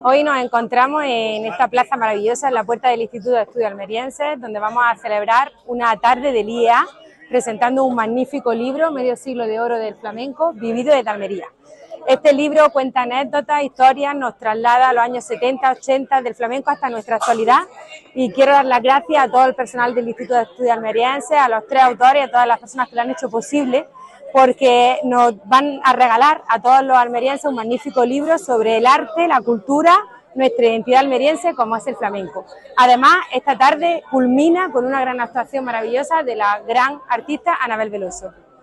20-06_libro_iea_diputada.mp3